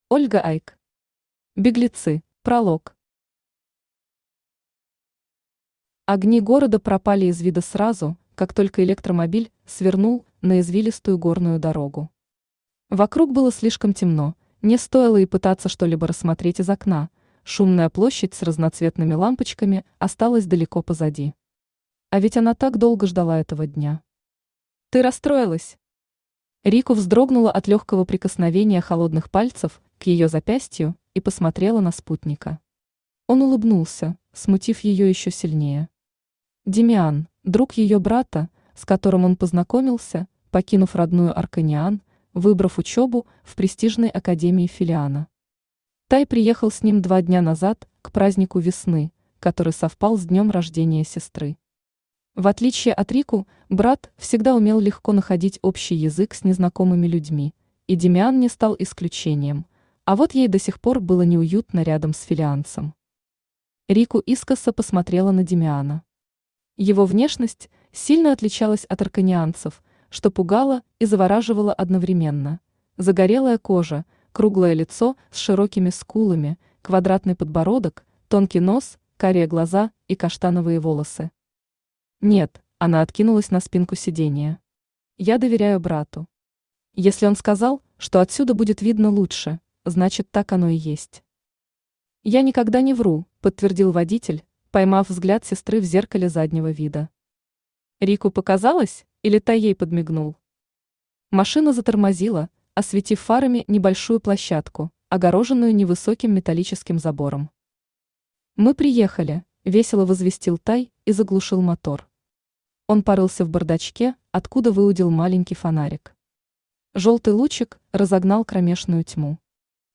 Aудиокнига Беглецы Автор Ольга Айк Читает аудиокнигу Авточтец ЛитРес.